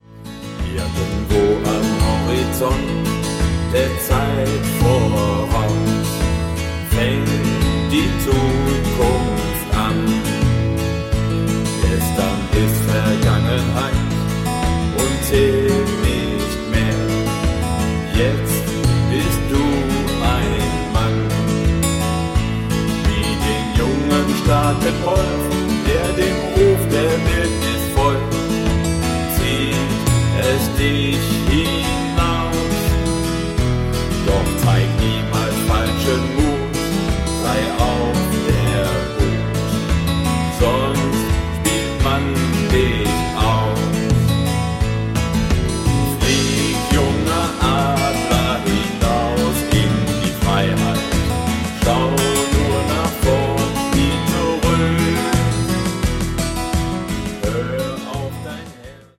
--- Country ---